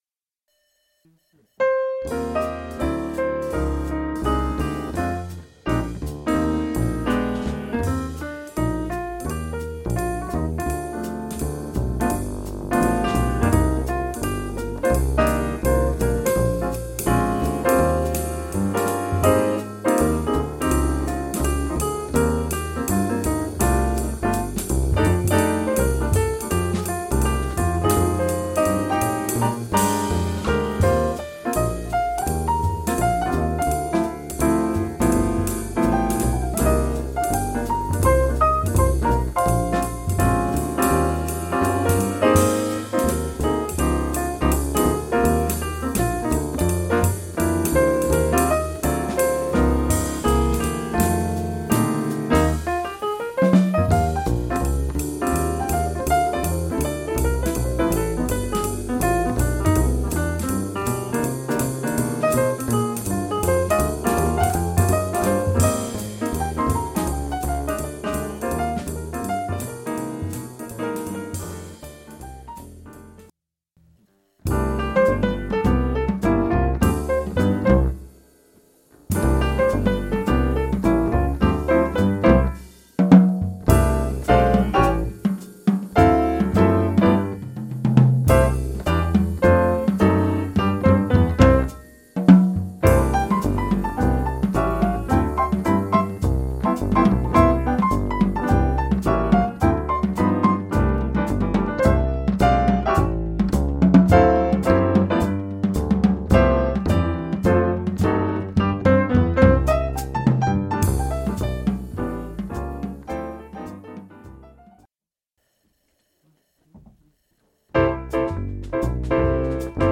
Playing standards instrumentally with jazz Trio